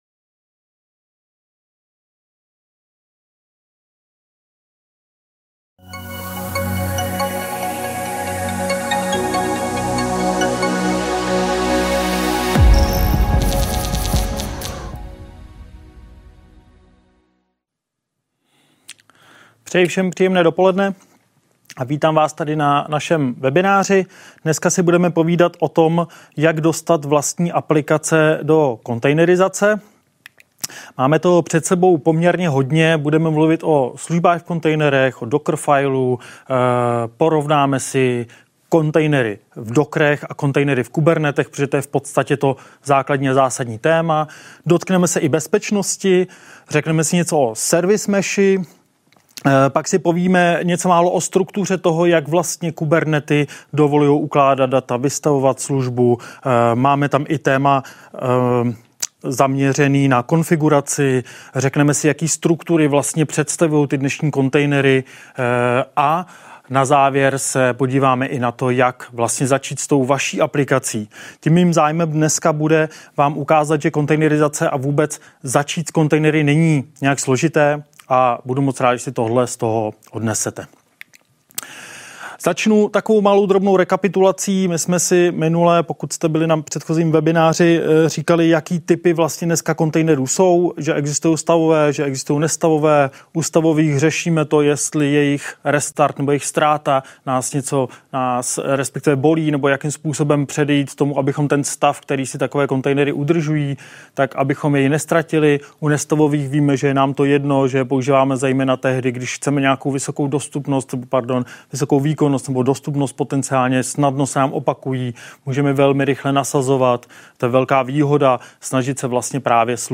Vítám vás tady na našem webináři. Dneska si budeme povídat o tom, jak dostat vlastní aplikace do kontejnerizace.